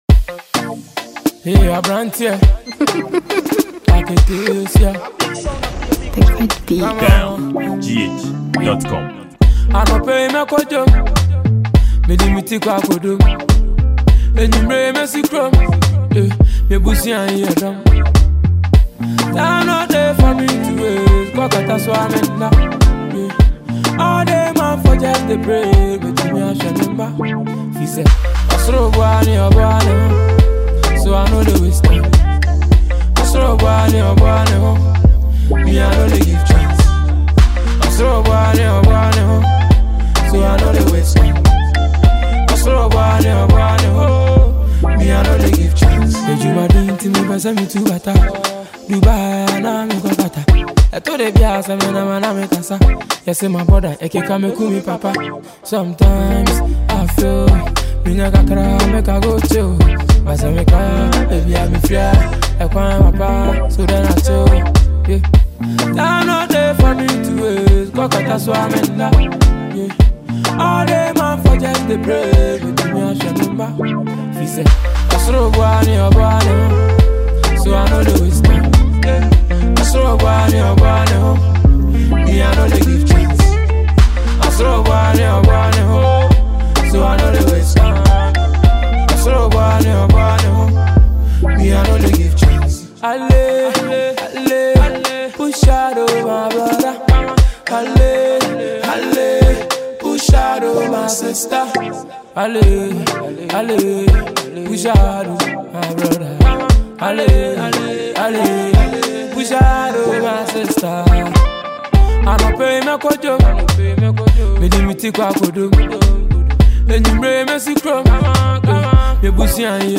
a popular and hit highlife song for mp3 download.